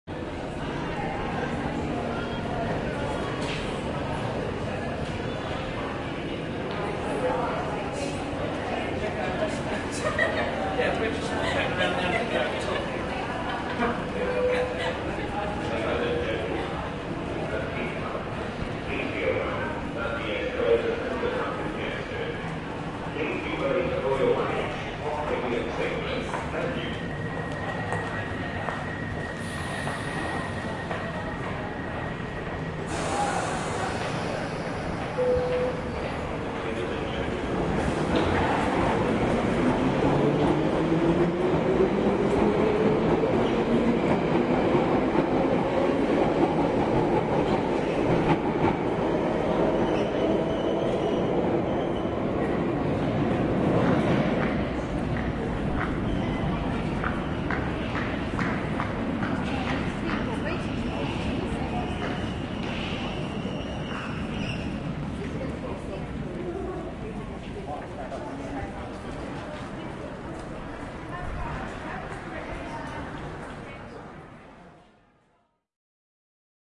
莆田站抗疫广播
描述：十年二十年后，这种声音会让我们想起2020至2021年新冠病毒肆虐全世界的记忆。录音时间：2021年5月19日；录音器材：iPhone 12 with AVR X